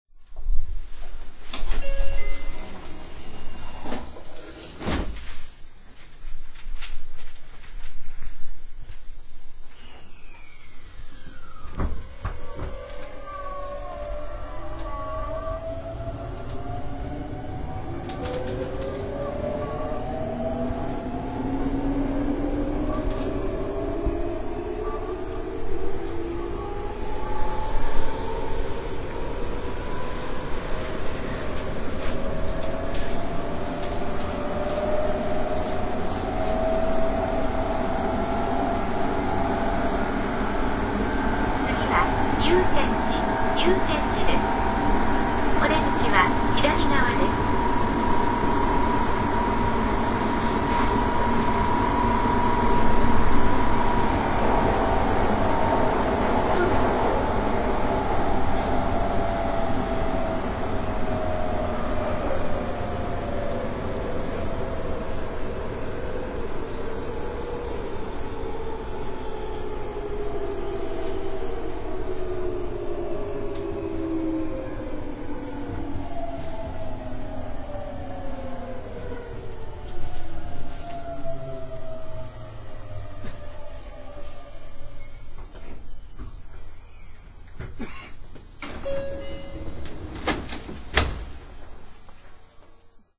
東洋電機製の後期ＧＴＯ−ＶＶＶＦインバータを装備します。
ＰＡＲＴ１　学芸大学〜祐天寺 （７５２ＫＢ）